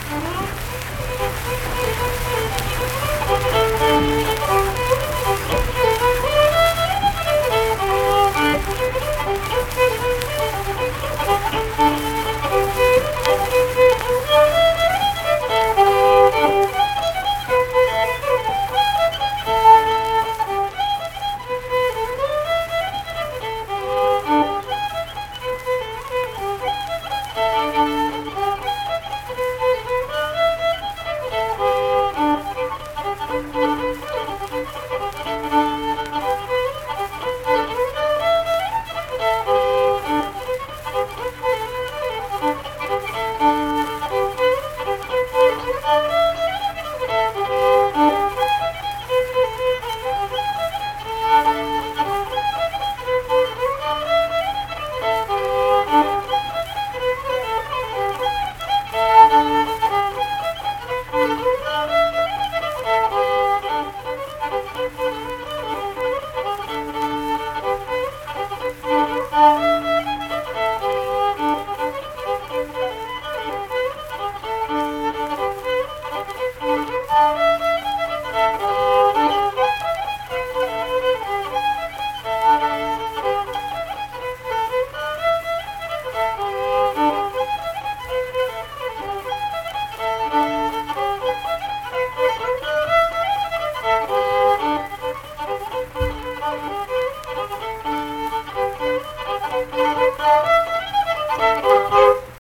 Unaccompanied fiddle music performance
Instrumental Music
Fiddle
Harrison County (W. Va.)